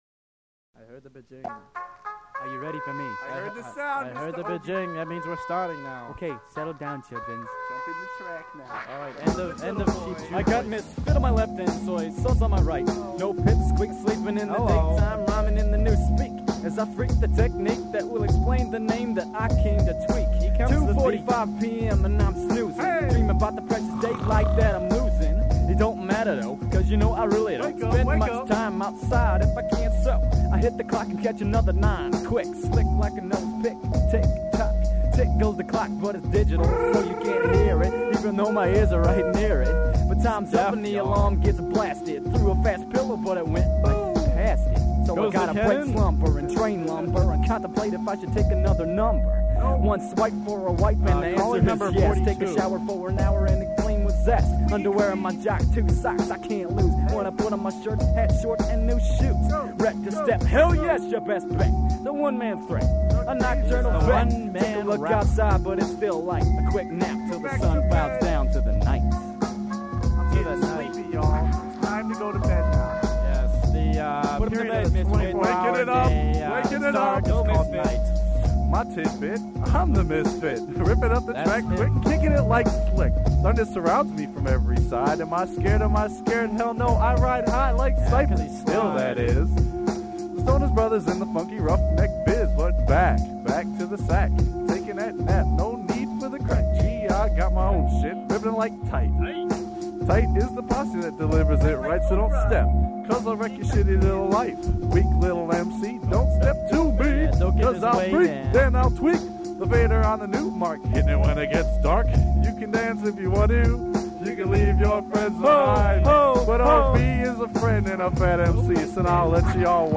posse cut
on the boom bap.